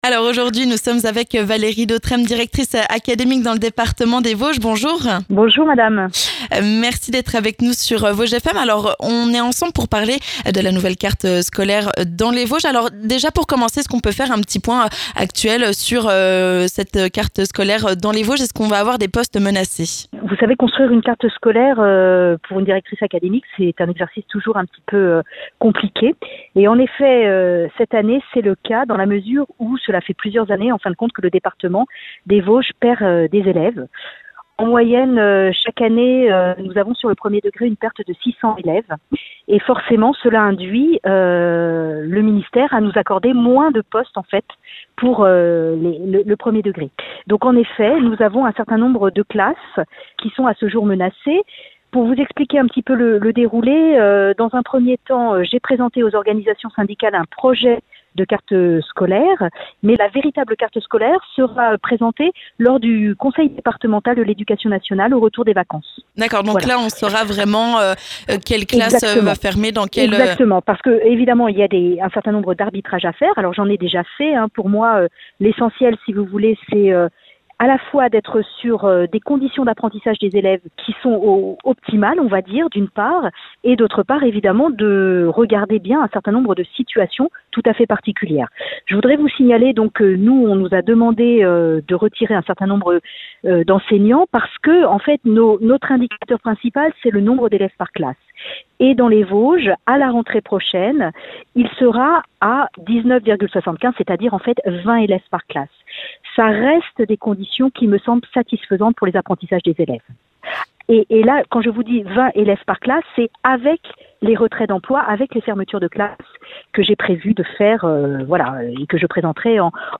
On en parle avec Valérie Dautresme, directrice académique des services de l'éducation nationale des Vosges.